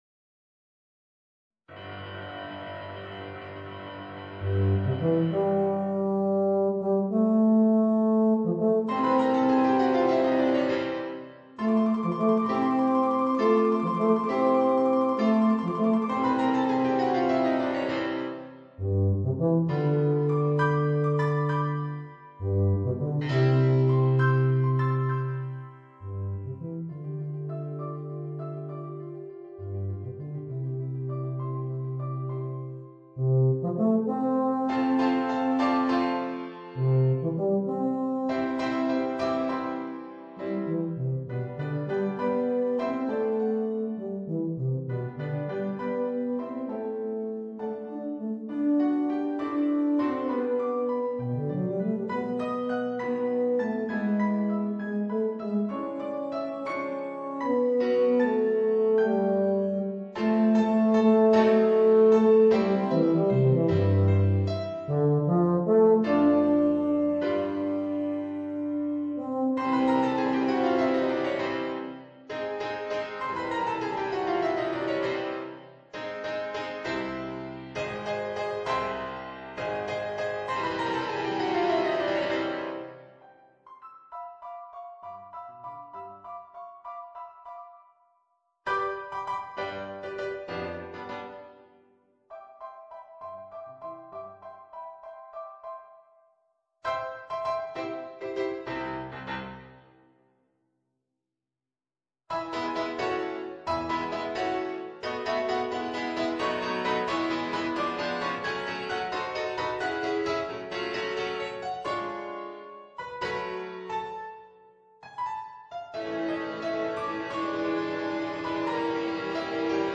Voicing: Tuba and Piano